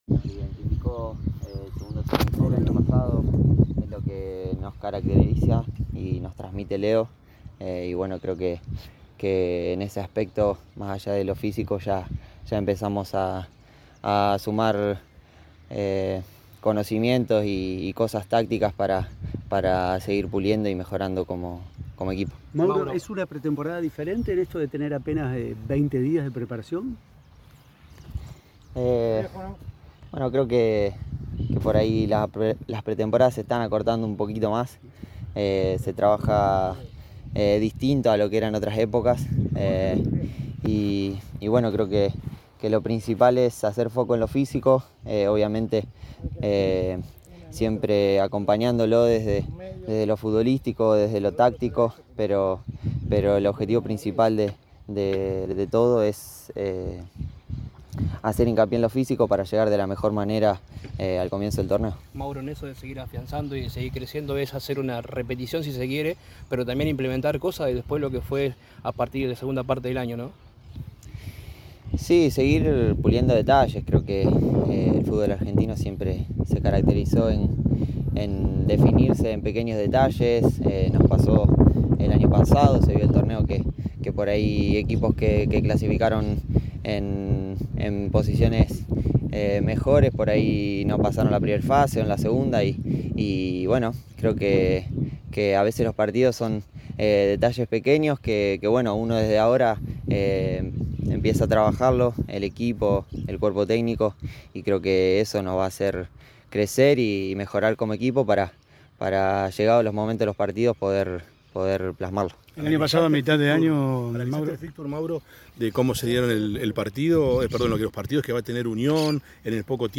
Tras un entrenamiento a puertas abiertas en Casa Unión, el mediocampista habló del trabajo físico, la identidad que busca el cuerpo técnico y la conformación del plantel para la temporada 2026.
En ese contexto, Mauro Pittón dialogó con EME y dejó definiciones claras sobre los objetivos del plantel de cara a la temporada 2026.